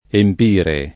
emp&re] (meno com. empiere [%mpLere]) v.; empio [%mpLo], empi — da empiere il pres. ind. (tranne la 2a pl. empite [emp&te], molto più com. di empiete [